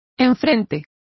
Complete with pronunciation of the translation of opposite.